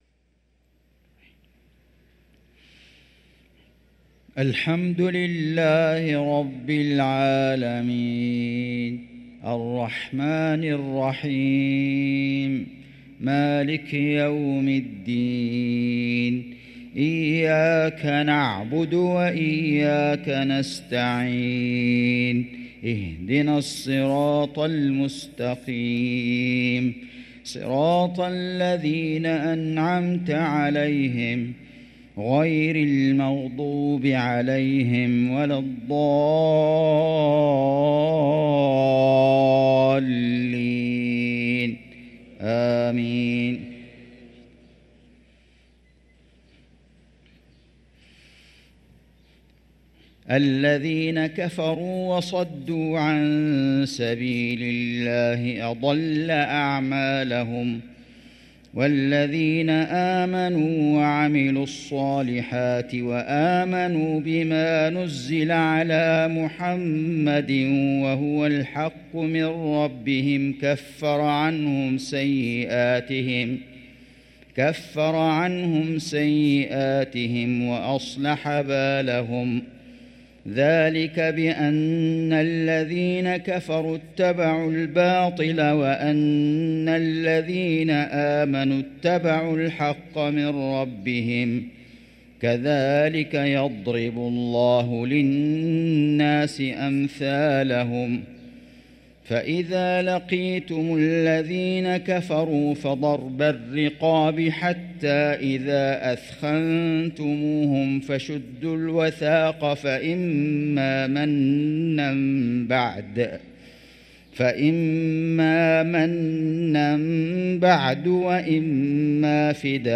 صلاة العشاء للقارئ فيصل غزاوي 24 جمادي الأول 1445 هـ
تِلَاوَات الْحَرَمَيْن .